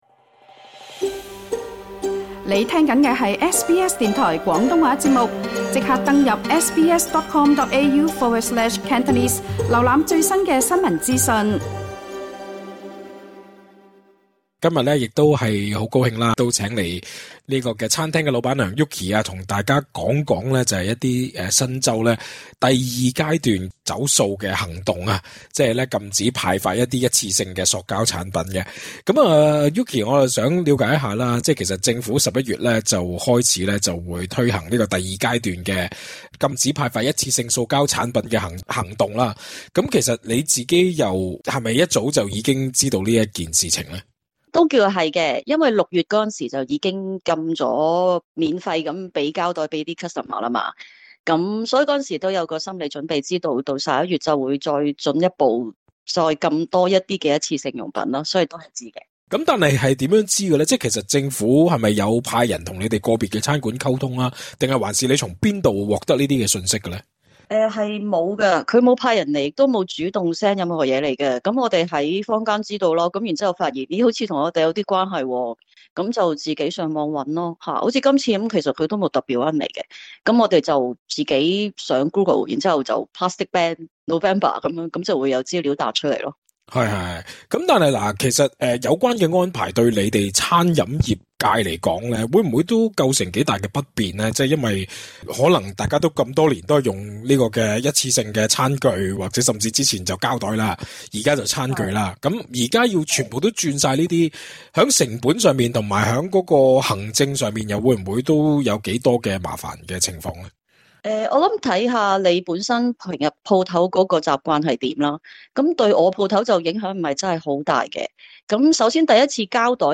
社區訪問